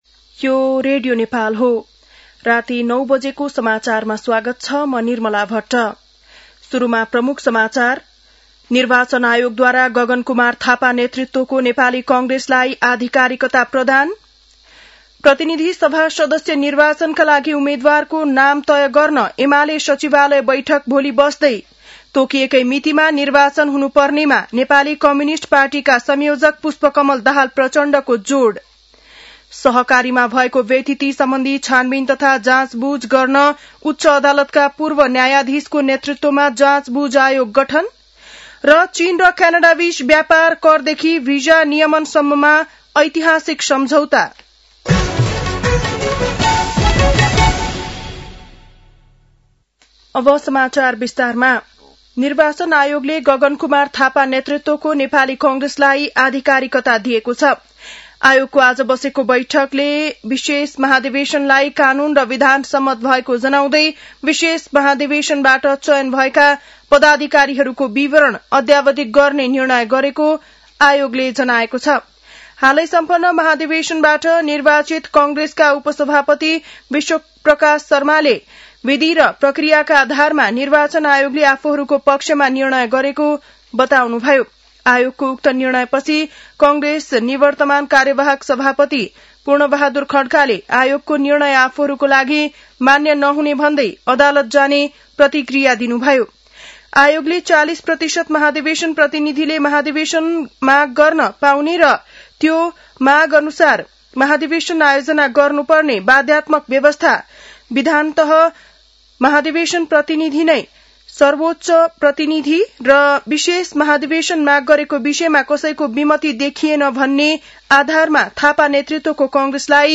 बेलुकी ९ बजेको नेपाली समाचार : २ माघ , २०८२